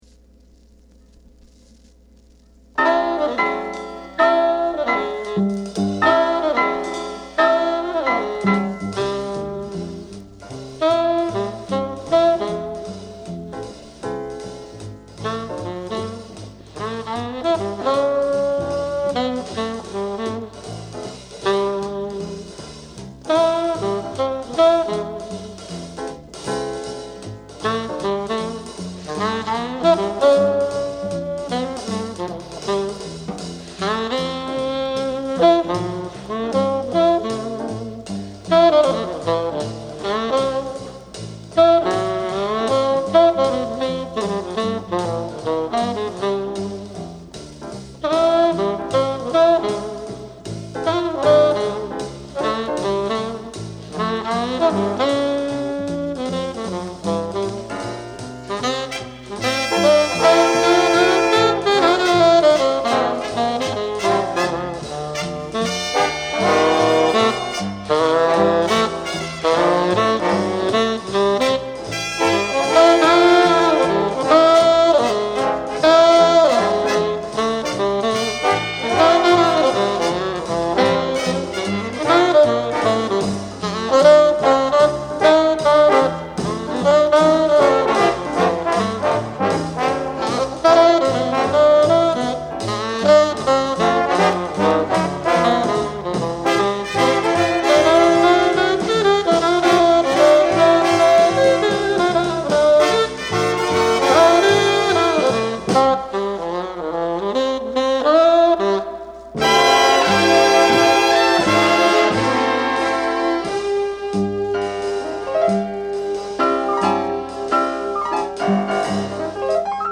Genre: Big Band Jazz / Swing
帯なし・ライナーノーツ付き 1962年5月1日、ニューヨークのA&Rスタジオで録音。